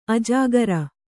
♪ ajāgara